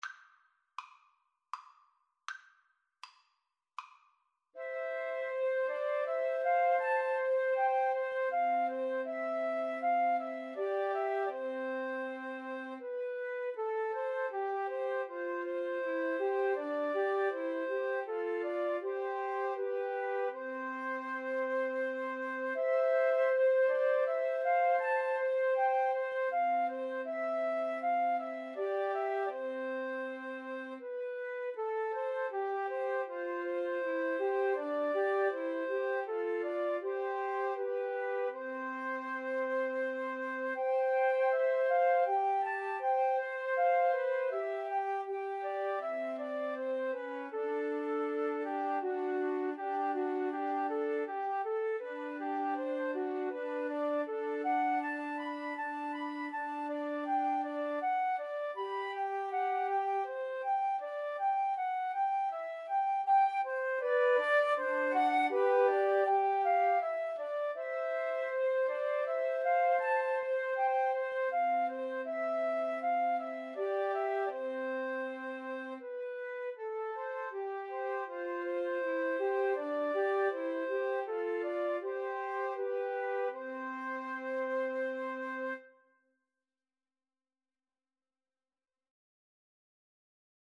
Free Sheet music for Flute Trio
C major (Sounding Pitch) (View more C major Music for Flute Trio )
Andante